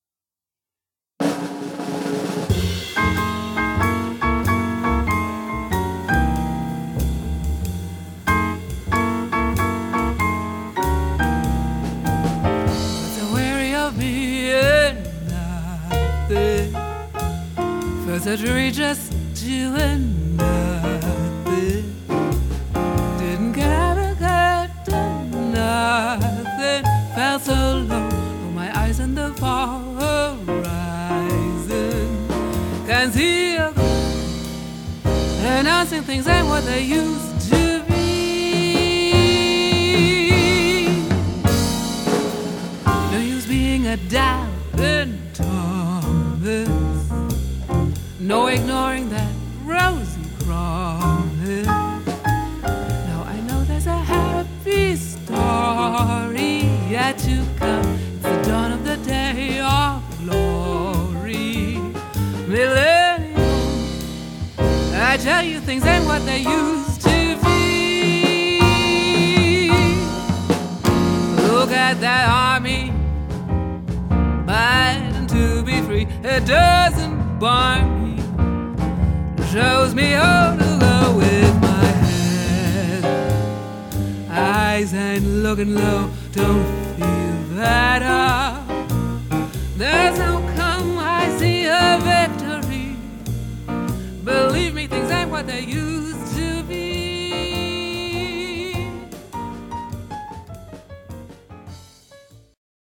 Von tiefstem Dunkelblau ist der Blues